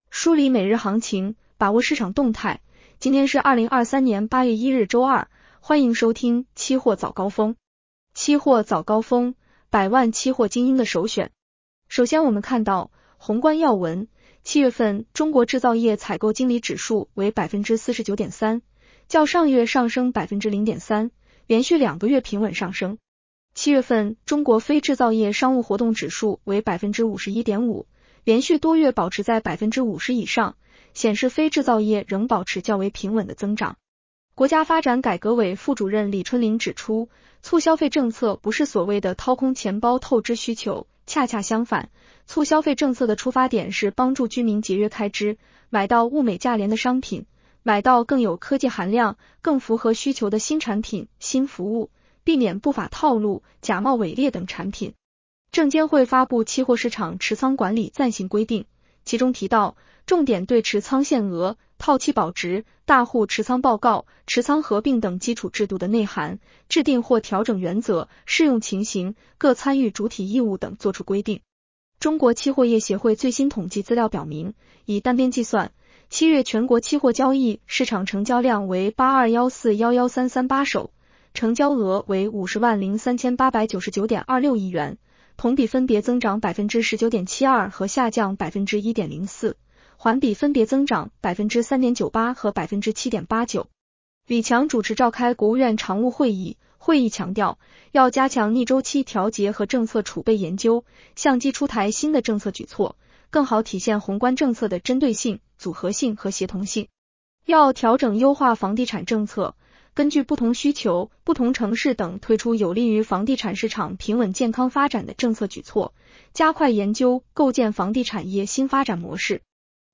【期货早高峰-音频版】 女声普通话版 下载mp3 宏观要闻 1. 7月份中国制造业采购经理指数为49.3%，较上月上升0.3个百分点， 连续两个月平稳上升 。